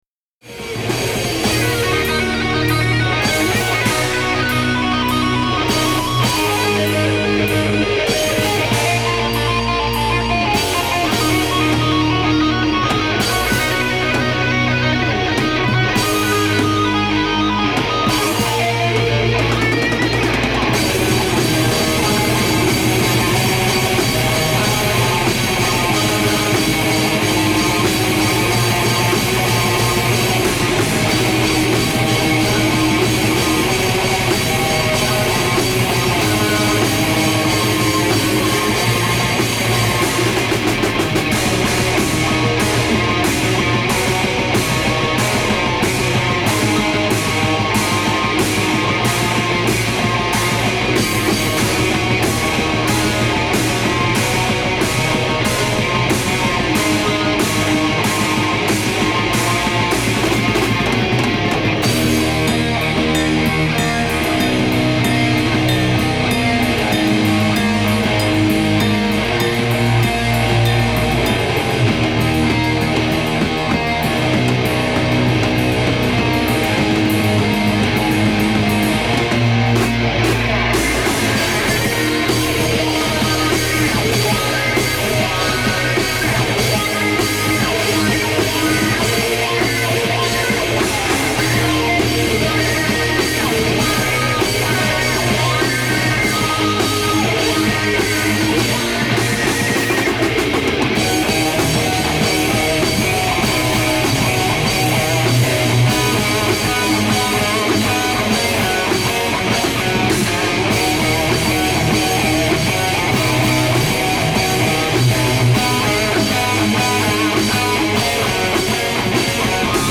death metal band